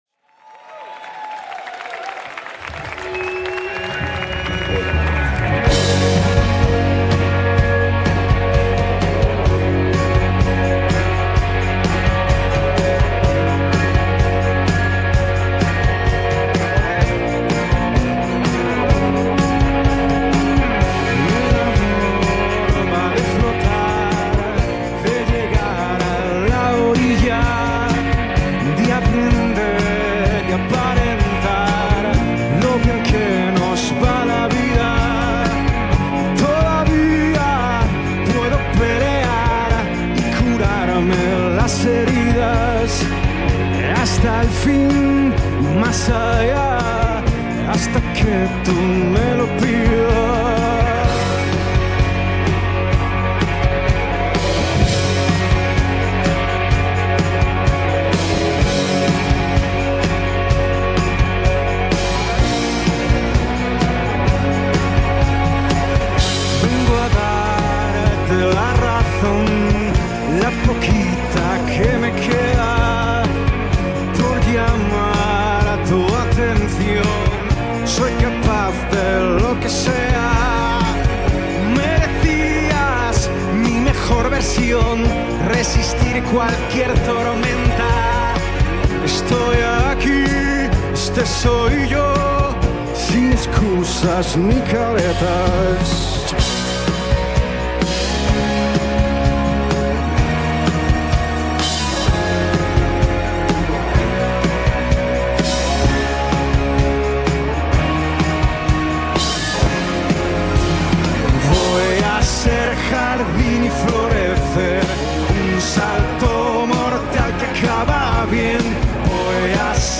recorded at La Radio Encandida